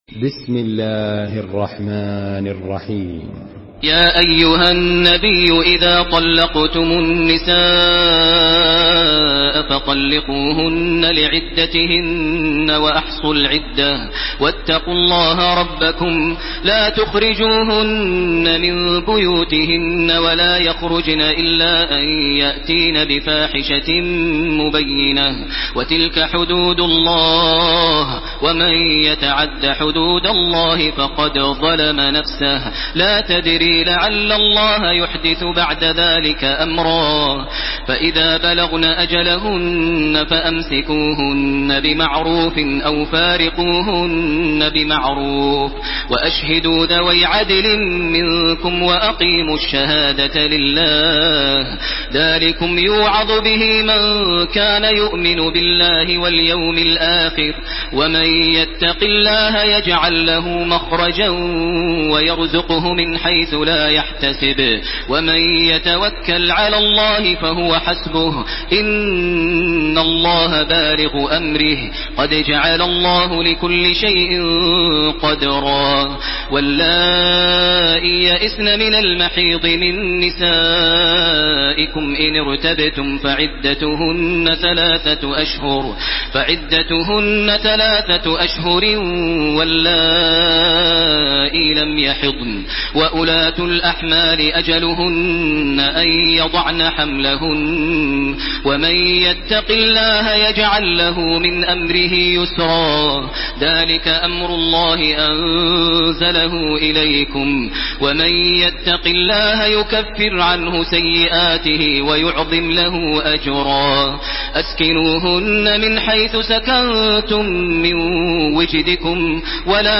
Surah At-Talaq MP3 in the Voice of Makkah Taraweeh 1431 in Hafs Narration
Murattal